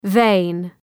Προφορά
{veın}